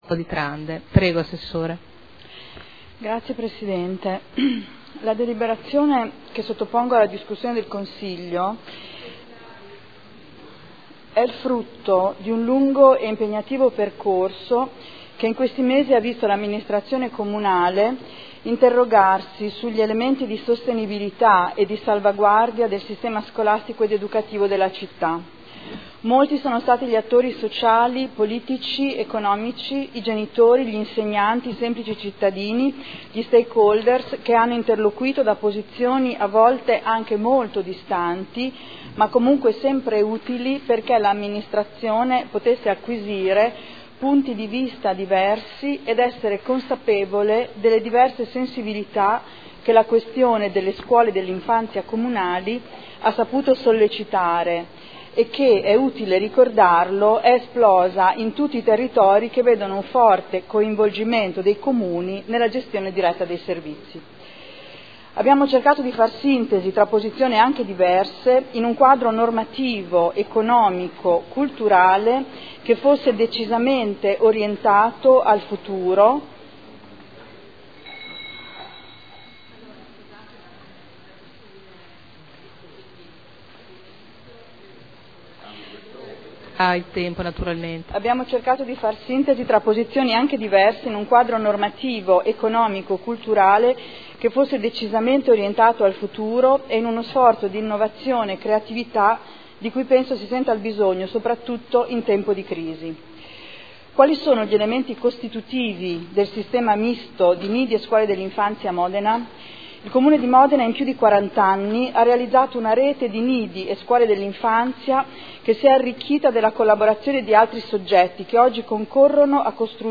Seduta del 03/05/2012. Proposta di deliberazione: Linee di indirizzo per la costituzione di una Fondazione finalizzata a gestire servizi scolastici ed educativi comunali 0/6 anni